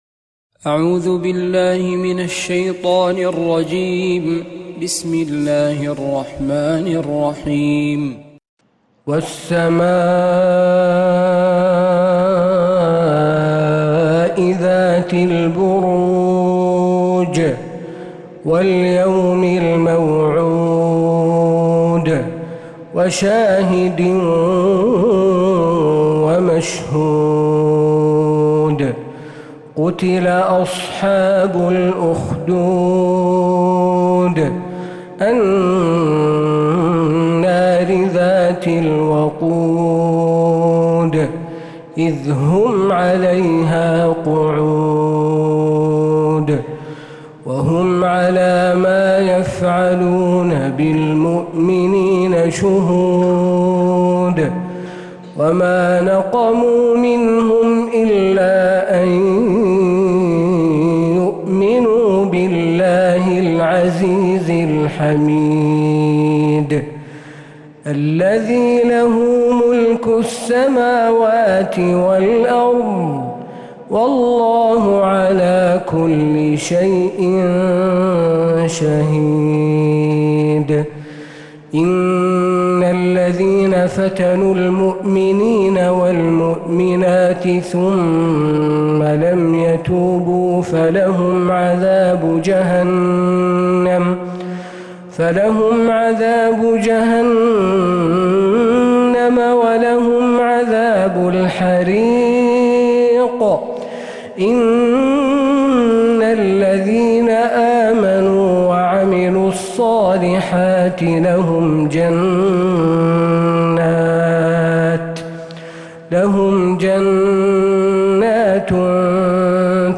سورة البروج كاملة من عشائيات الحرم النبوي